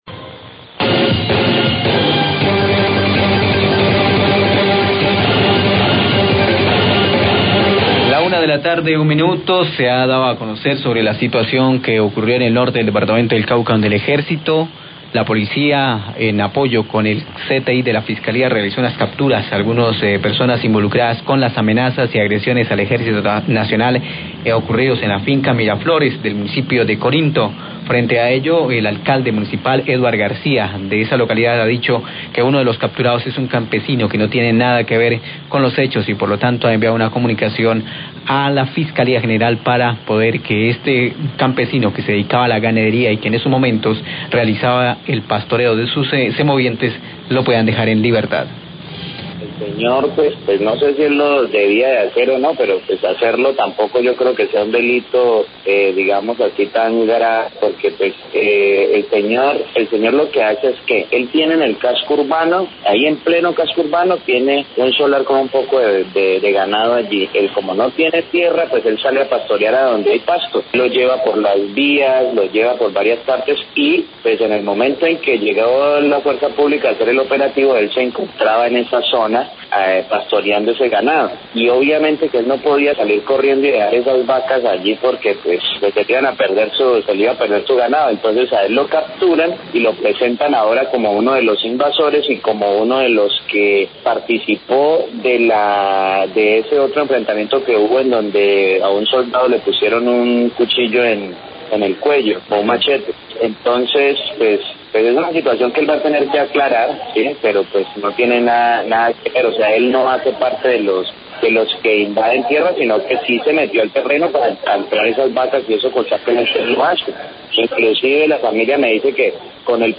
Radio
Se ha dado a conocer sobre situación que ocurrió en el Norte del Cauca donde el Ejército, Policía y CTI realizaron unas capturas a 2 personas que amenazaron al Ejército hechos ocurridos en la hacienda Miraflores, Corinto. Declaraciones del Alcalde de Corinto.